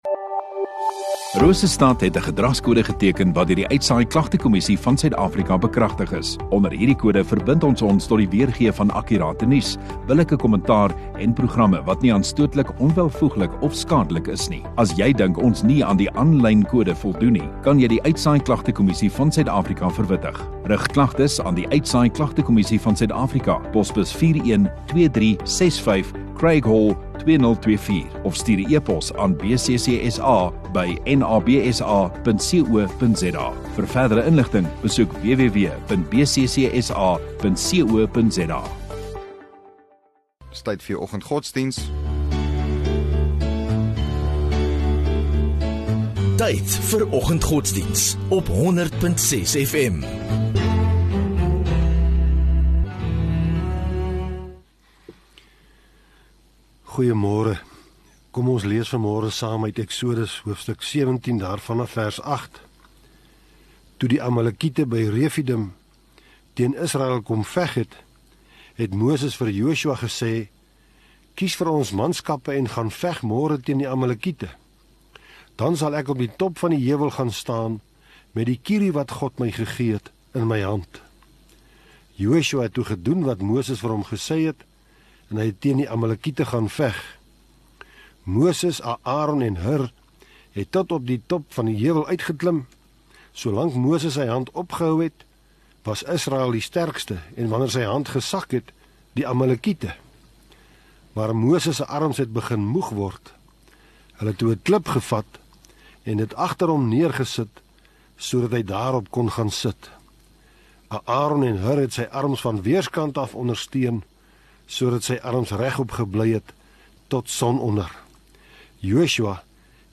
4 Mar Dinsdag Oggenddiens